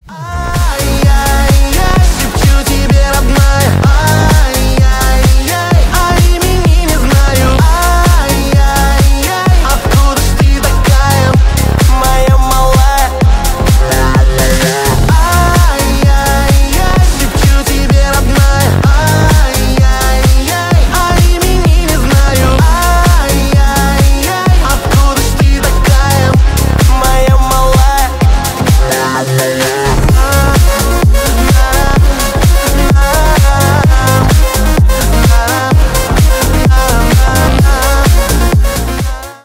Ремикс
клубные